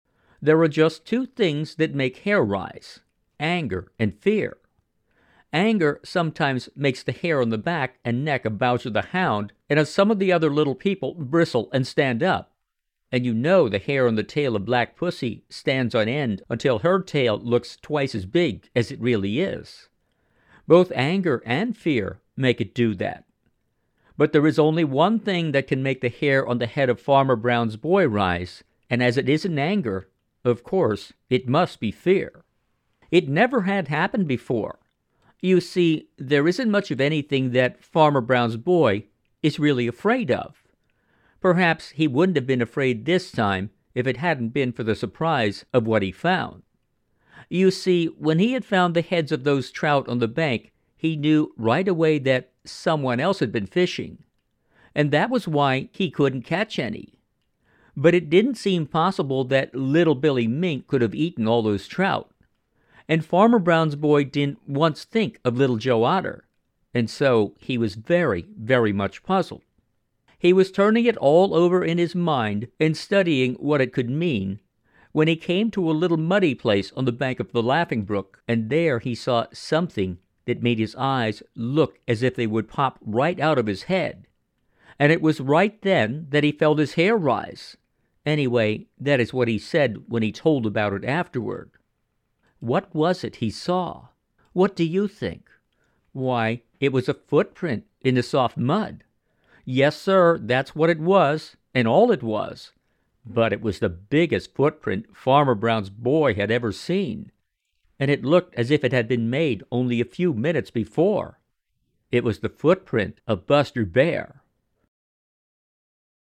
Children's and adult audiobooks